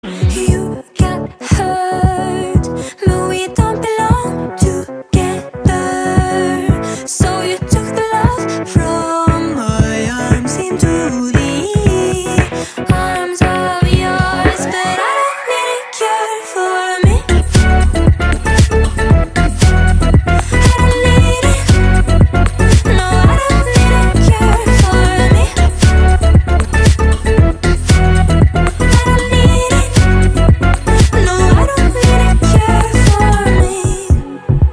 Catégorie POP